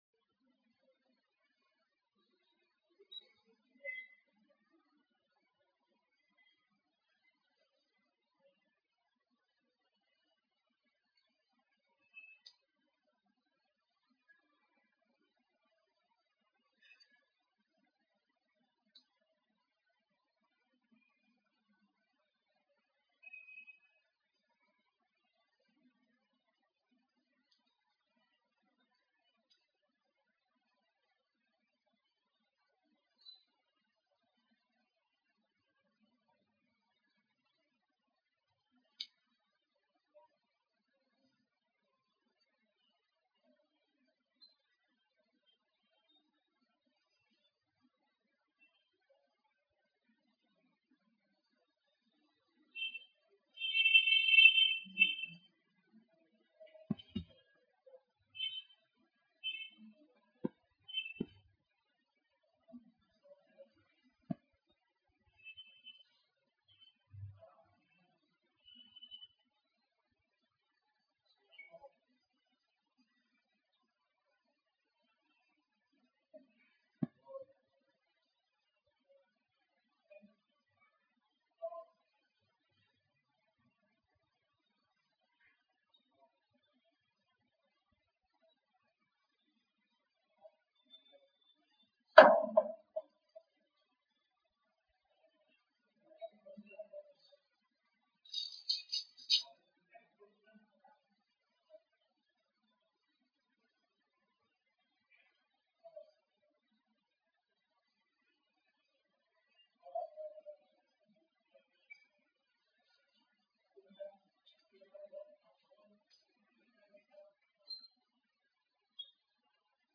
شرح الأربعين النووية 14 - معهد ابن تيمية الفرقة التمهيدية - الشيخ أبو إسحاق الحويني